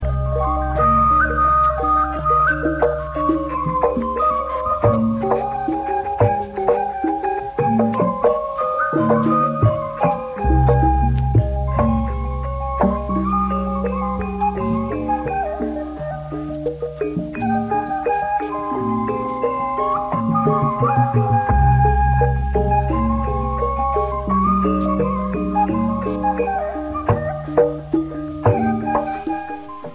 Gamelan Music
Degung from West Java, 60k
degung.ra